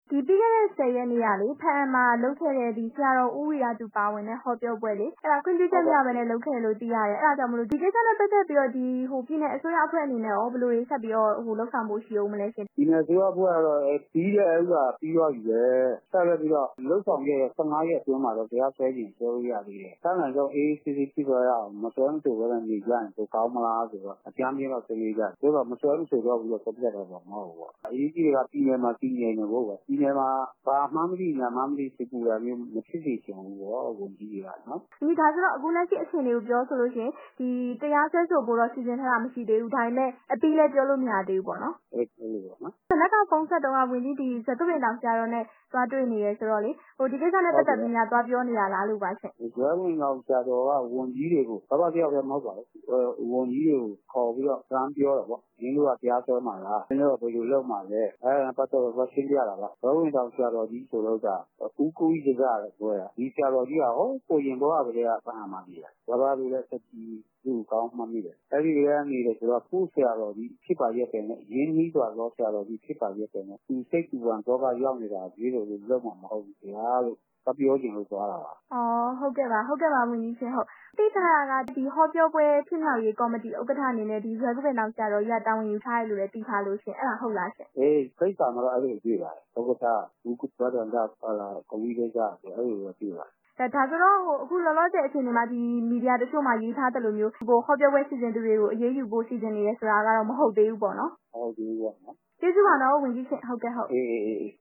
ကရင်ပြည်နယ်၊ ဘားအံမြို့မှာ ခွင့်ပြုချက်မရဘဲ ကျင်းပခဲ့တဲ့ ဆရာတော် ဦးဝီရသူ ပါဝင်တဲ့ဟောပြောပွဲ စီစဉ်သူတွေကို လောလောဆယ် တရားစွဲဆိုဖို့ အစီအစဉ်မရှိသေးဘူးလို့ ကရင်ပြည်နယ် အစိုးရအဖွဲ့ မွန်တိုင်းရင်းသားရေးရာ ဝန်ကြီး ဦးမင်းတင်ဝင်း က ပြောပါတယ်။